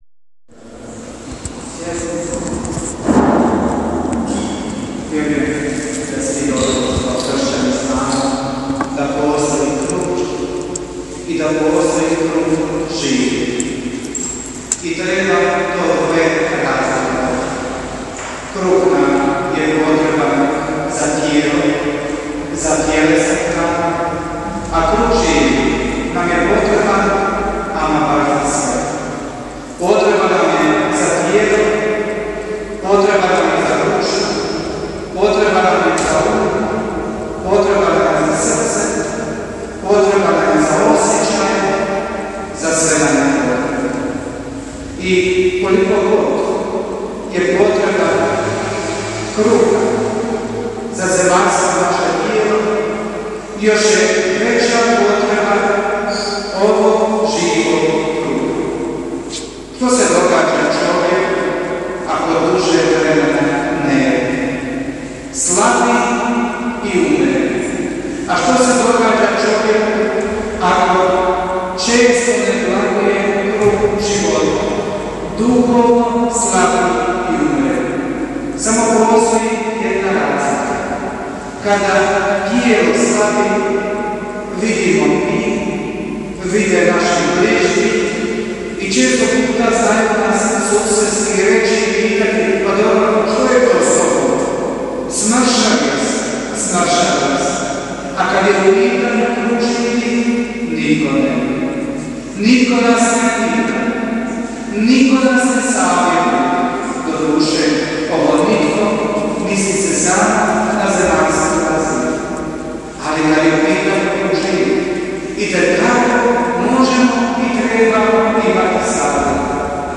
KRATKA PROPOVIJED – TIJELOVO
DONOSIMO KRATKU PROPOVJED SA VEČERNJE SV. MISE.